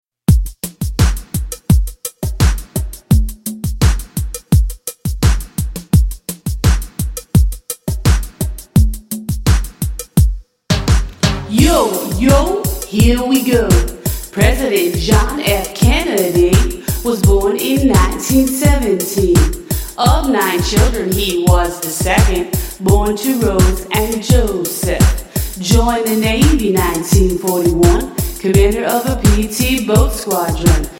MP3 Demo Vocal Track